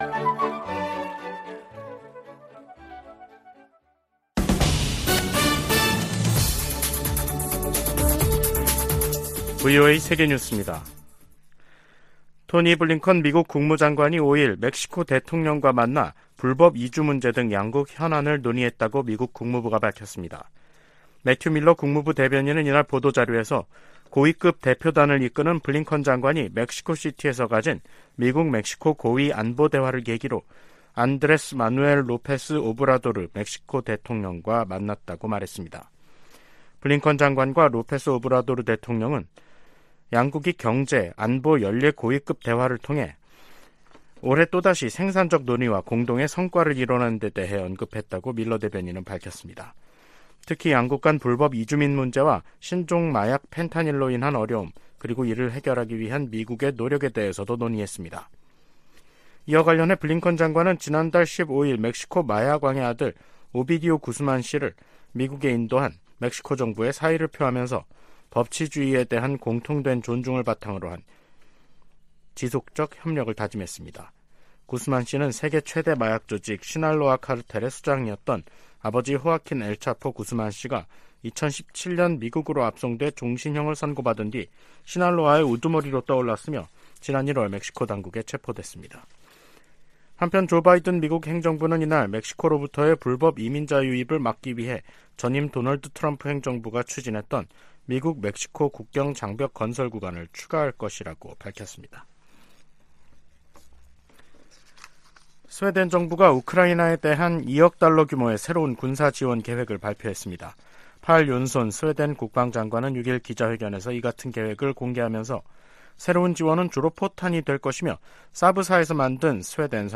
VOA 한국어 간판 뉴스 프로그램 '뉴스 투데이', 2023년 10월 6일 3부 방송입니다. 미 국방부는 최근 공개한 대량살상무기(WMD) 대응 전략을 정치·군사적 도발로 규정한 북한의 반발을 일축했습니다. 미국의 인도태평양 지역 동맹과 파트너십이 그 어느 때보다 강화되고 있으며, 미국의 가장 큰 전략적 이점 중 하나라고 미국 국방차관보가 말했습니다. 미 상원의원들이 올해 첫 한반도 안보 청문회에서 대북 정책을 실패로 규정하며 변화 필요성을 강조했습니다.